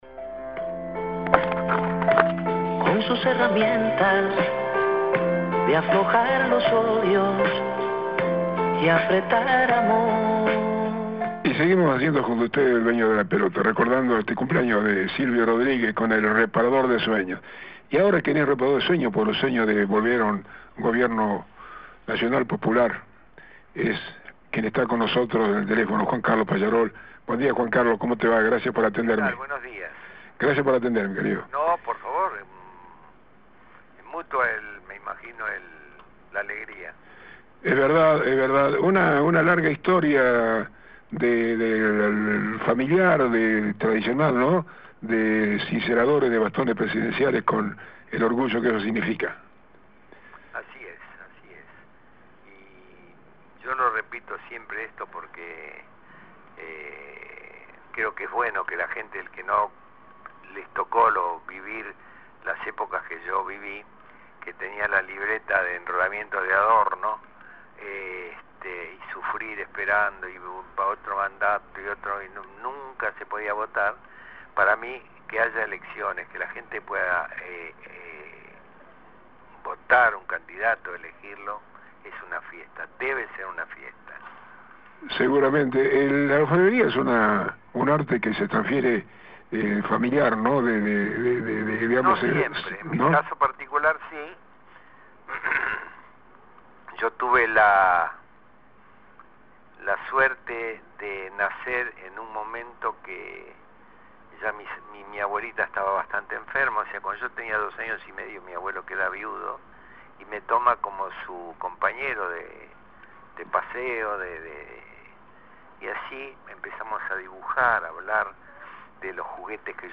Juan Carlos Pallarols, orfebre EL BAST�N ES DEL PUEBLO Entrevistamos a quien es, oficialmente, quien cincela los bastones presidenciales Pallarols estuvo en Villa Gesell y perimi� que centenares de geselinos y turistas dejar su marca en el atributo de mando que recibir� el 10 de diciembre, Alberto Fern�ndez